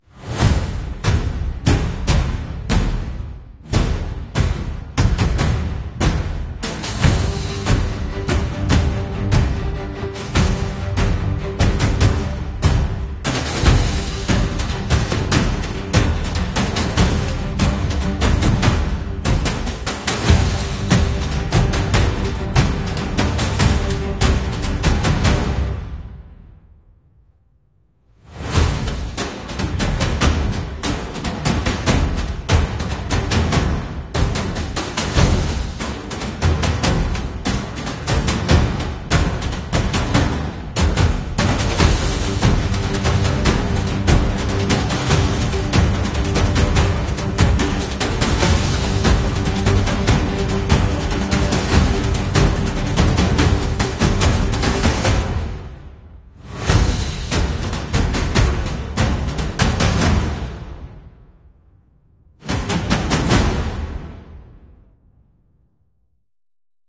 📍Pernyataan ini disampaikan Prabowo saat meresmikan Gedung Layanan Terpadu & Institut Neurosains Nasional di RS PON Mahar Mardjono, Jakarta Timur, Selasa (26/8).